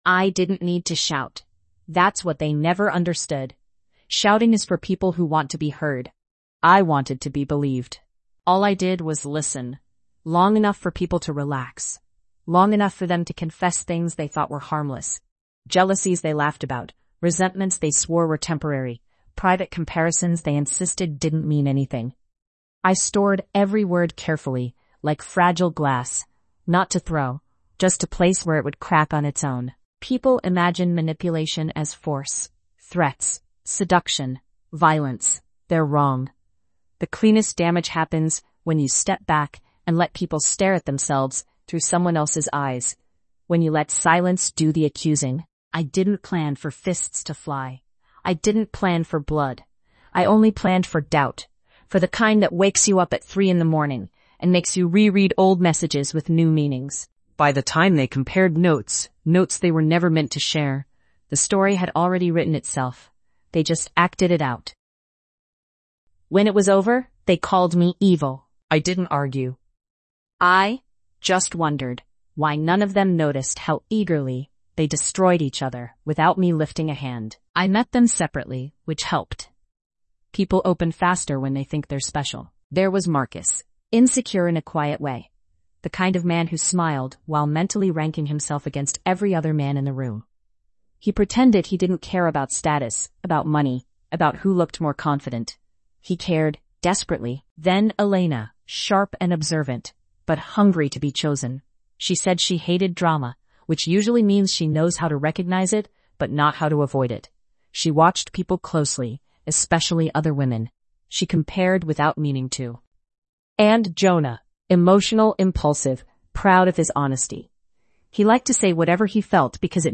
This episode is a first-person psychological confession from a woman who weaponized intimacy and watched a triangle destroy itself.